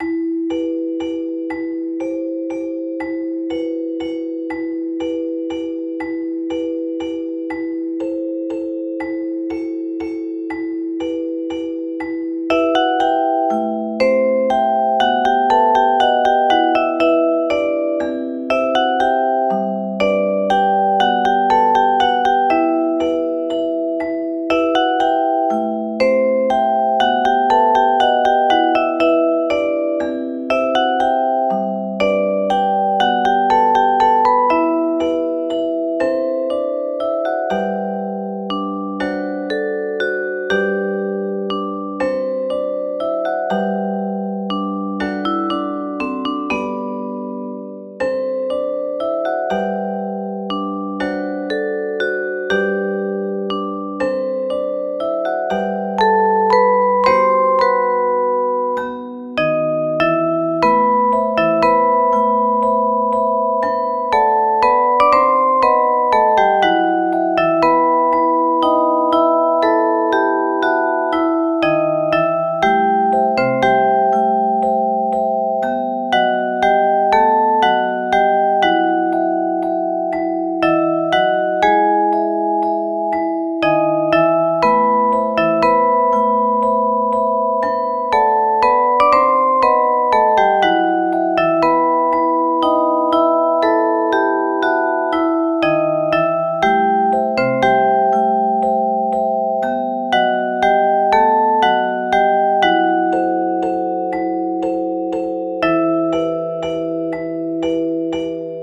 オルゴール版 - Music box edition
【イメージ】悲しいワルツ など